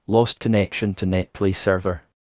netplay_lost_connection.wav